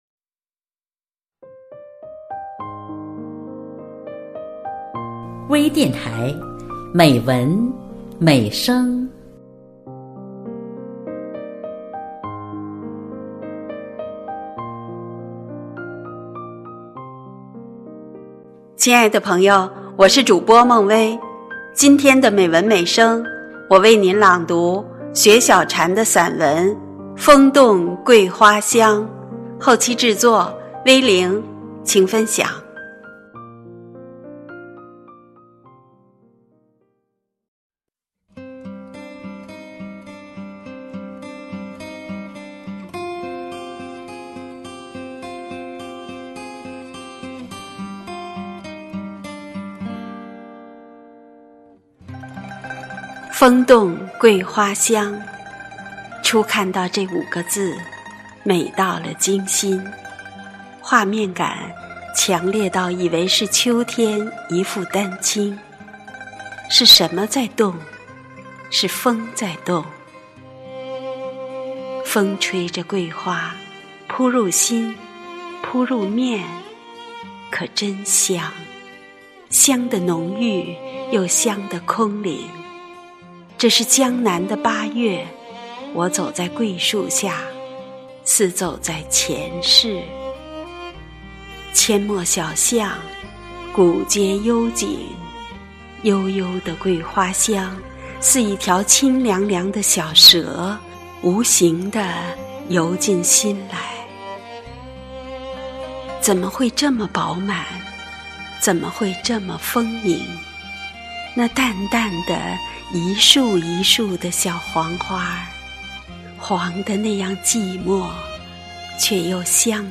美文美声